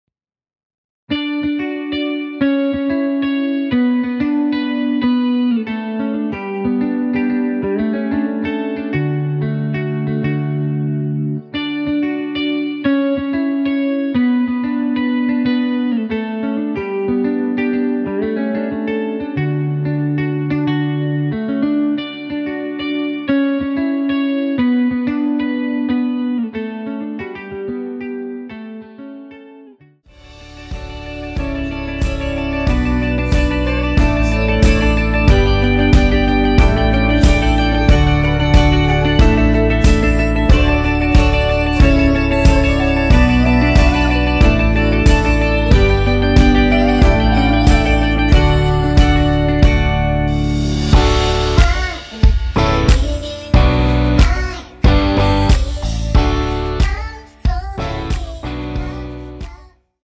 원키에서(+5)올린 (2절 삭제)하고 진행 되는 코러스 포함된 MR입니다.
앞부분30초, 뒷부분30초씩 편집해서 올려 드리고 있습니다.
중간에 음이 끈어지고 다시 나오는 이유는